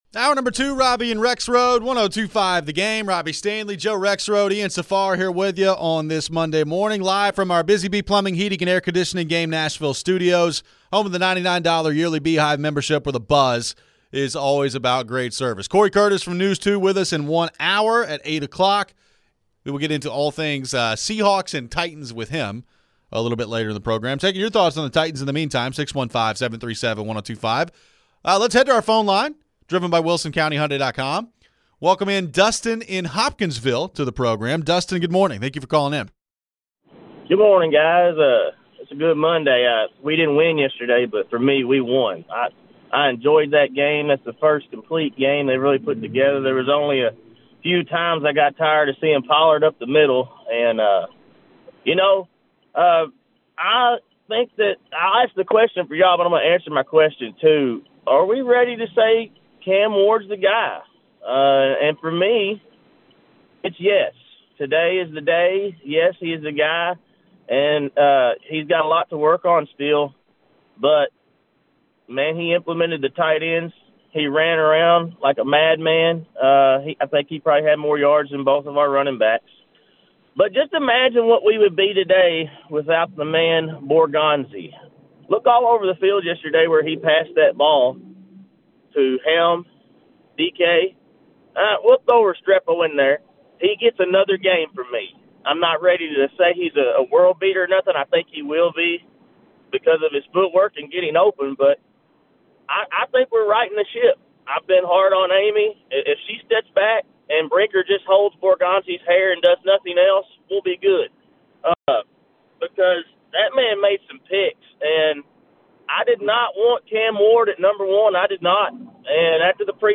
We continue our conversation on the Titans and signs of improvement from Cam Ward. We get back to the phones. We react to the busy weekend of college football. Vanderbilt and Diego Pavia looked good with a 45-17 win over Kentucky.